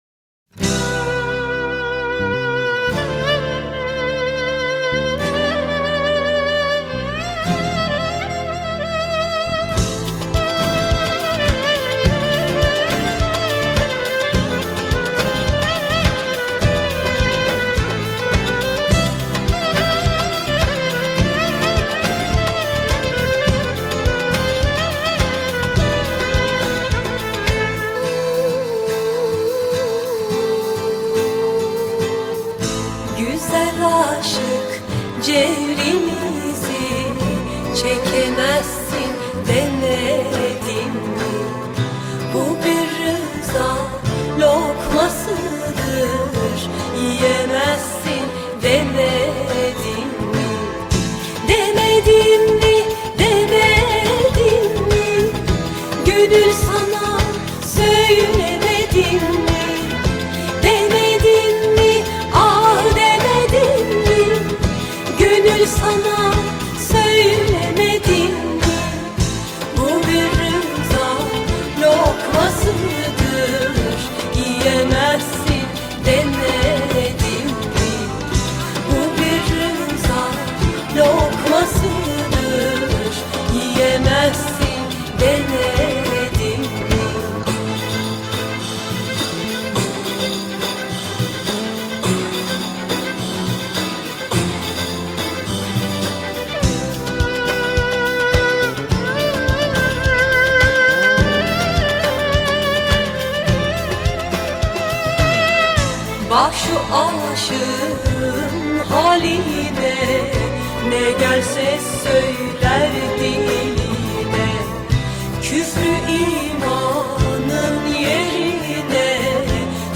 Zahmet etti stüdyomuza geldi ve zikir yaptı.